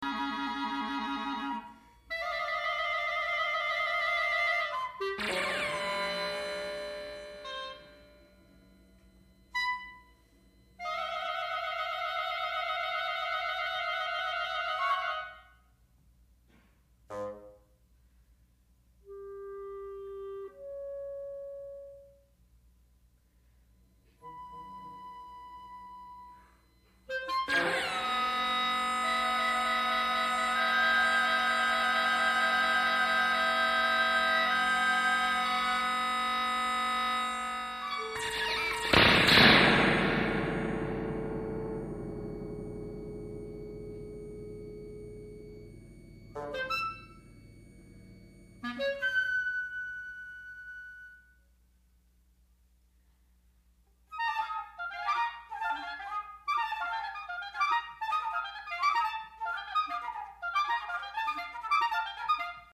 (flute, oboe, clarinet, bassoon, ARP 2600 or electronics)
The score is traditionally notated and includes several improvisational sections.